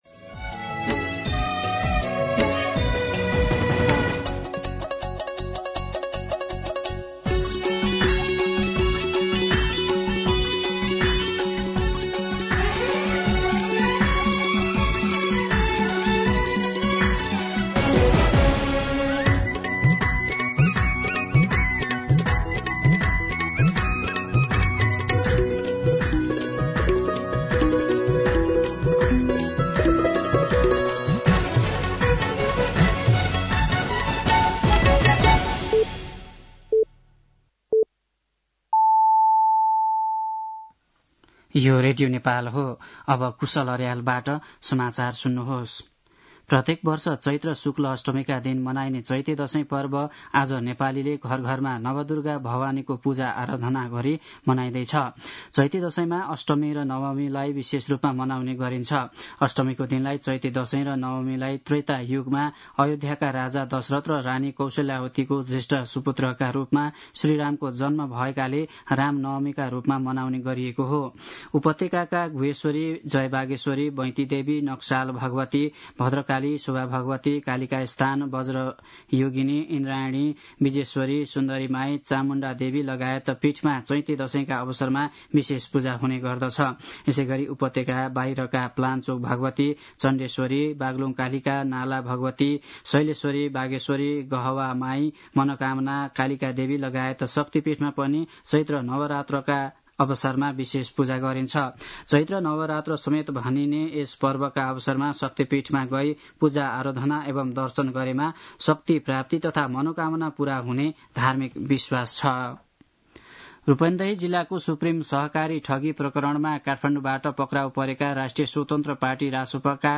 मध्यान्ह १२ बजेको नेपाली समाचार : २३ चैत , २०८१
12-pm-Nepali-News-2.mp3